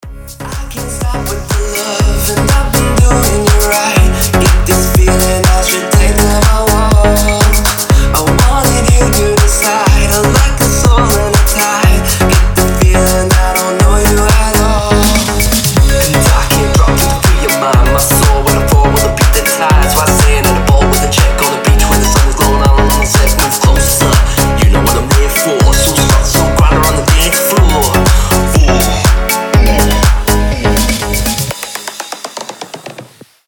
• Качество: 320, Stereo
мужской голос
заводные
Dance Pop
Euro House
Заводная летняя музыка